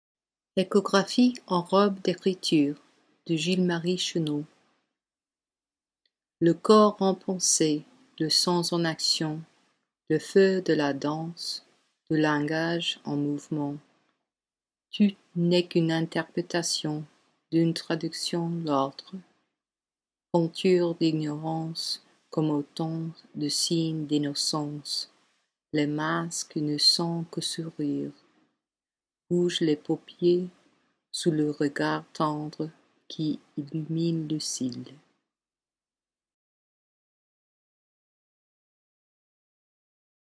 An audio version of the English translation is available below: